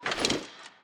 equip_iron5.ogg